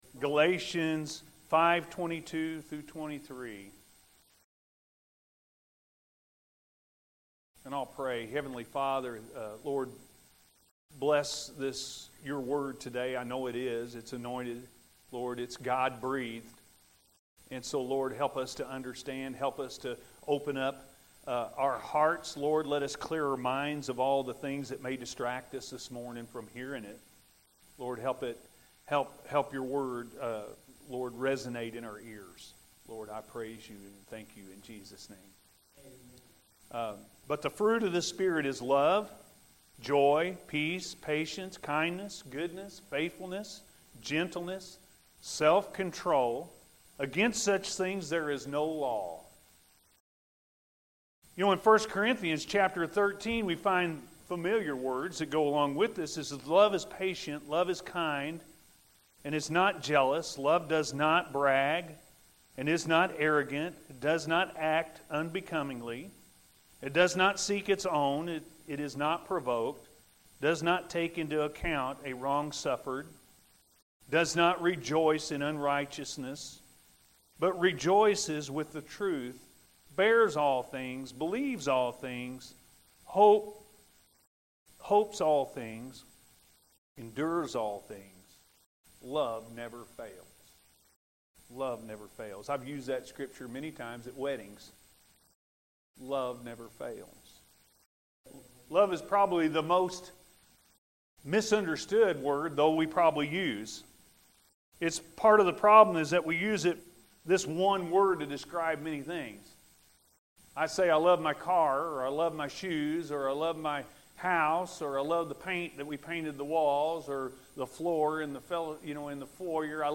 Love Never Fails-A.M. Service – Anna First Church of the Nazarene